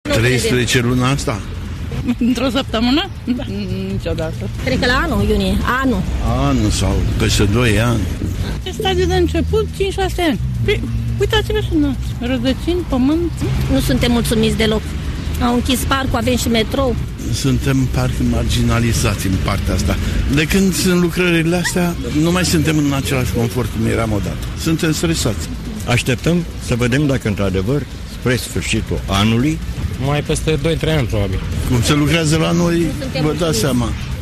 Bucurestenii sunt sceptici cand vad privelistea si spun ca poate la anul pe vremea asta se vor putea relaxa in parc.
vox-parc.mp3